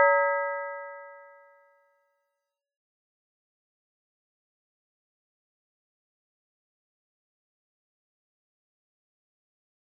例: 円盤の波動方程式の固有値, 固有関数(Bessel 関数)を利用して作った
円形のかね音1 , 円形のかね音2 .